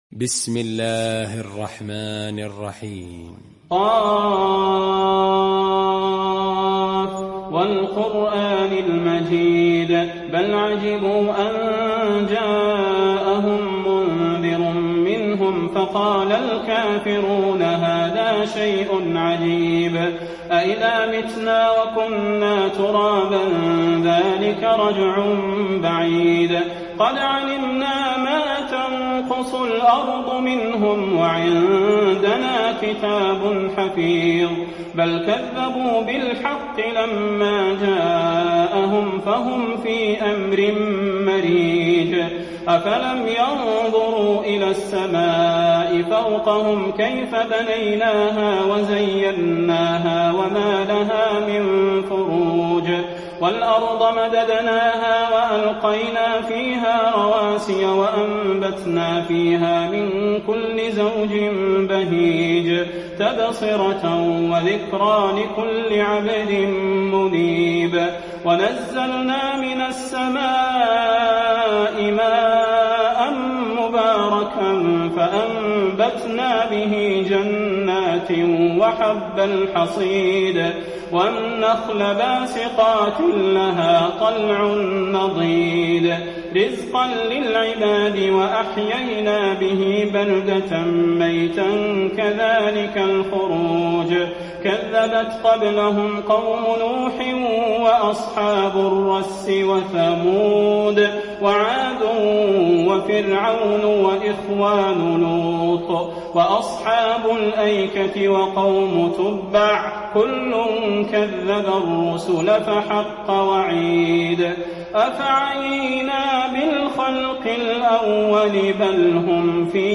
المكان: المسجد النبوي ق